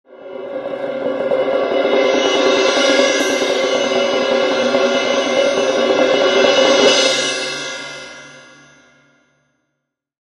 Звуки тарелки
На этой странице собраны звуки тарелок – яркие, резонансные и динамичные.